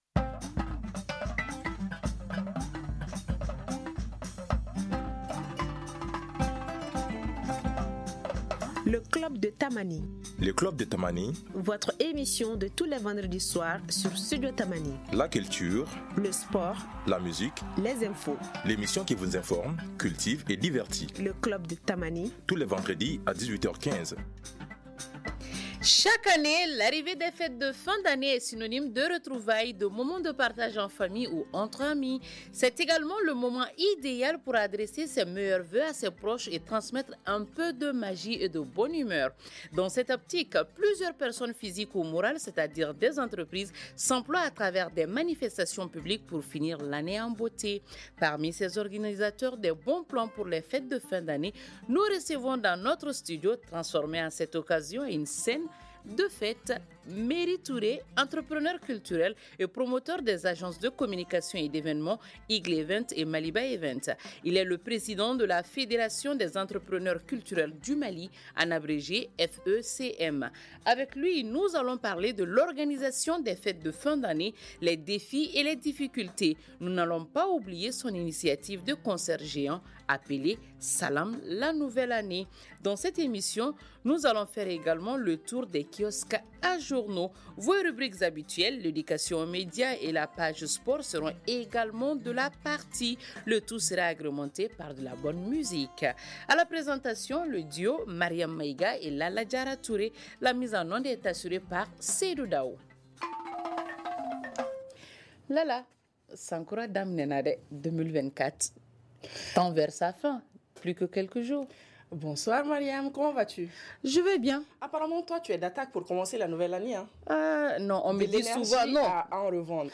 nous recevons dans notre studio, transformé à cette occasion en une scène de fête